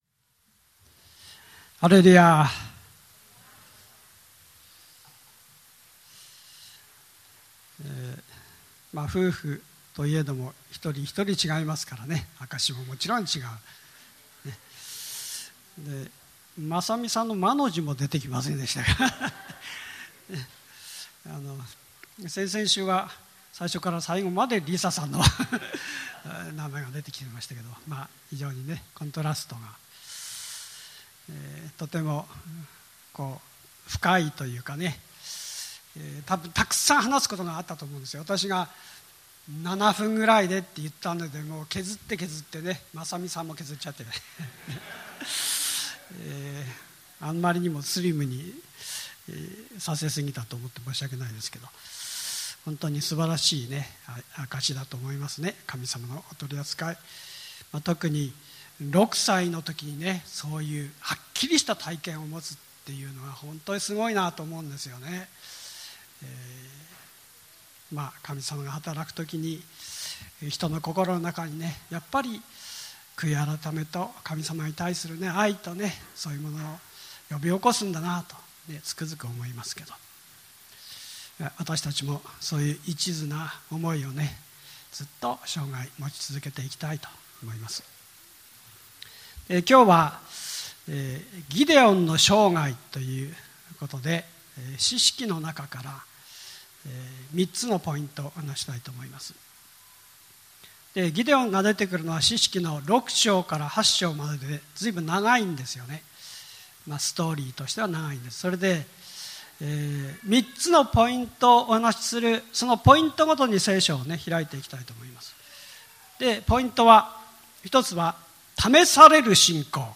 日曜礼拝